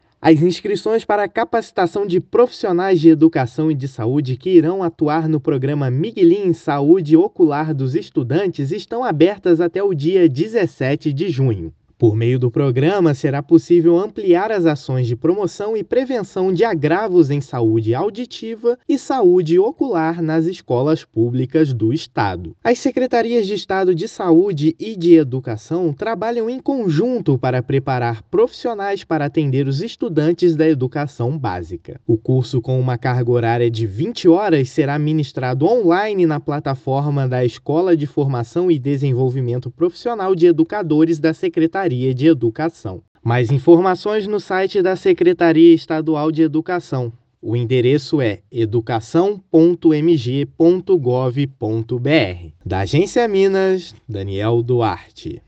[RÁDIO] Abertas as inscrições para capacitação de profissionais no Programa Miguilim
Com 5 mil vagas disponíveis, interessados têm até o dia 17/6 para garantir a oportunidade. Ouça a matéria de rádio: